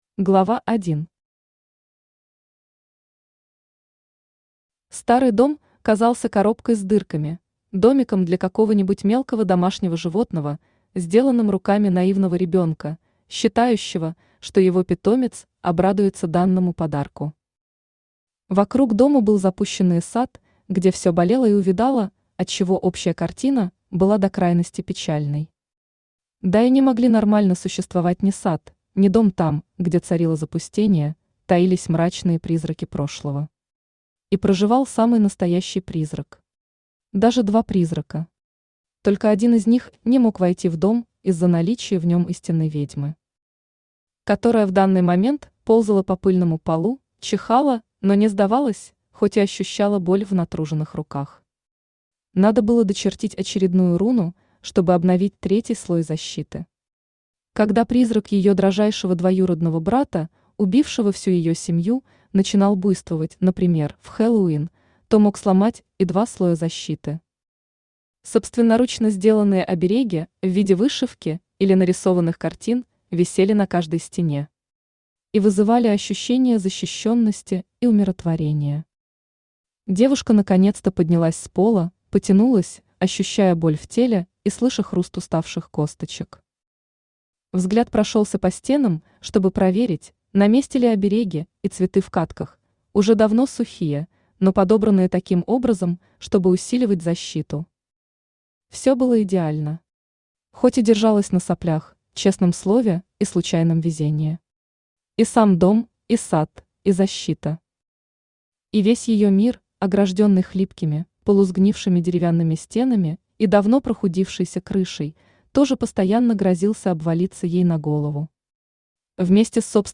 Aудиокнига Охота на ведьм Автор Кристина Воронова Читает аудиокнигу Авточтец ЛитРес.